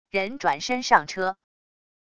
人转身上车wav音频